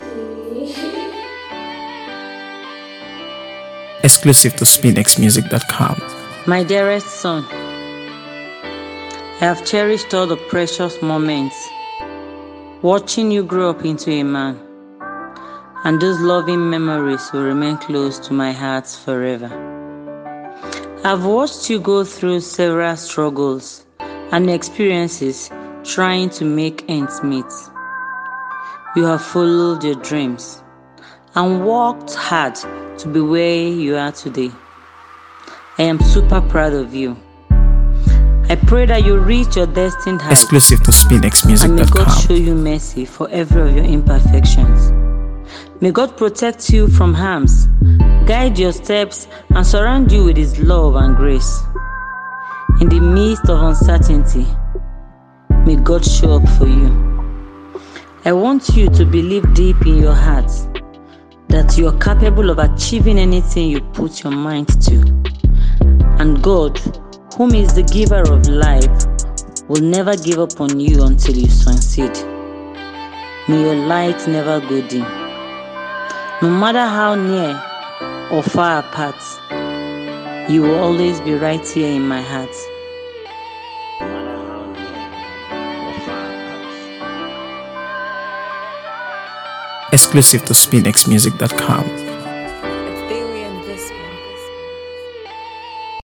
AfroBeats | AfroBeats songs
Hip Hop music